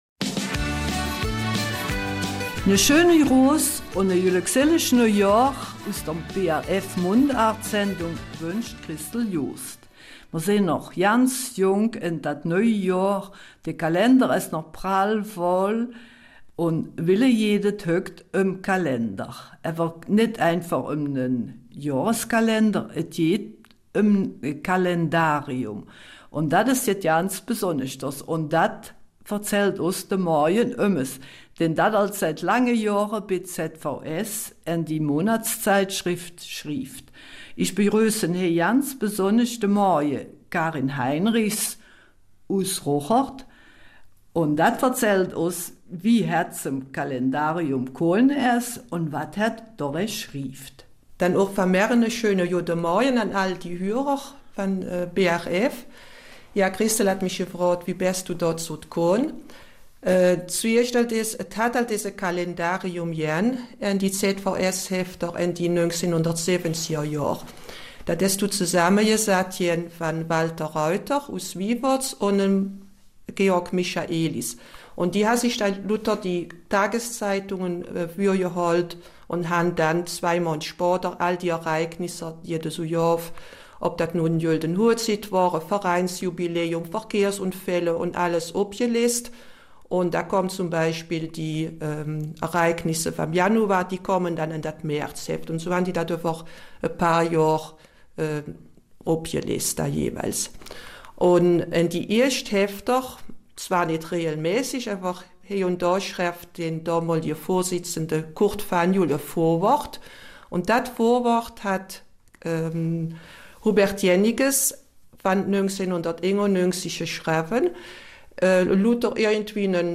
Eifeler Mundart - 14.